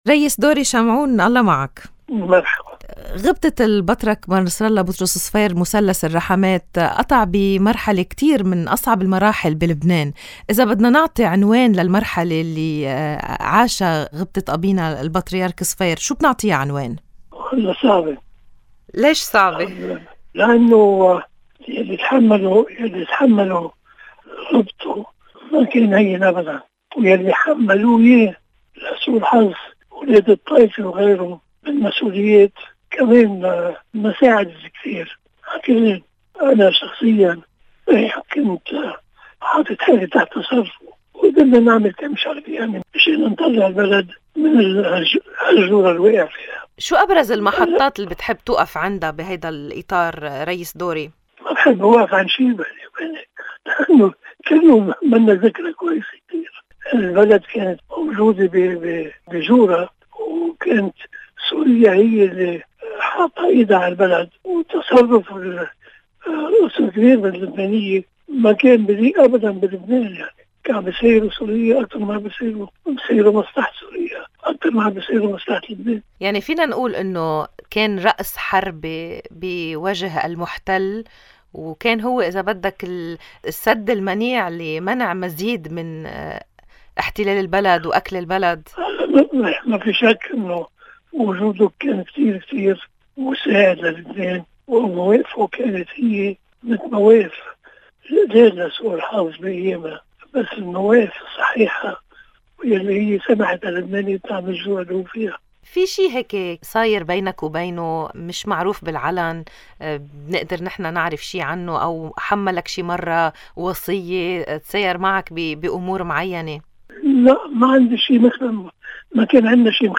قال النائب السابق دوري شمعون وردًا على سؤال للبنان الحر عن عنوان المرحلة التي عاشها البطريرك صفير: صعبة موضحًا أنّ  ما تحمّله  الكاردينال ليس سهلًا أبدًا وحتى ما حمّلوه إياه من مسؤوليات، وبعضهم من أبناء الطائفة،  وغيرهم لم يكن سهلًا. ولفت إلى أَنَّ كلّ المرحلة لم تكن ذكرى حلوة في لبنان لأن سوريا سيطرت على […]